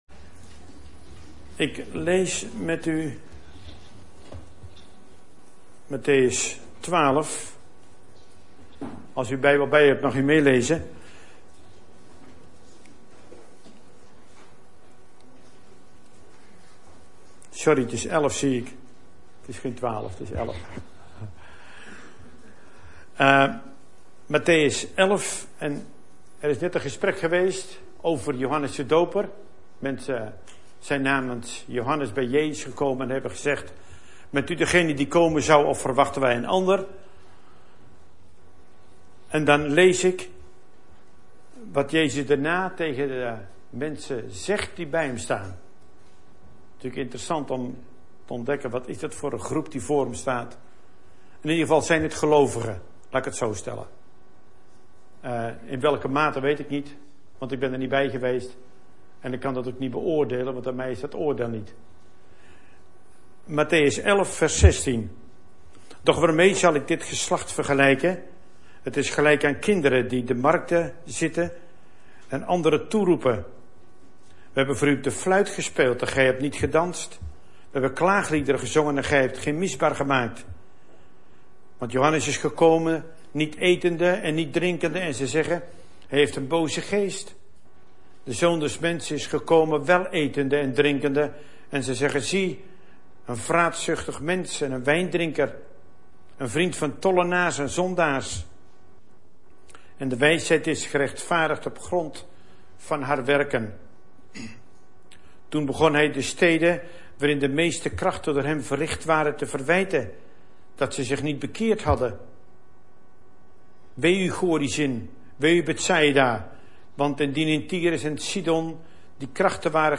In de preek aangehaalde bijbelteksten (Statenvertaling)Mattheus 11:16-2816 Doch waarbij zal Ik dit geslacht vergelijken?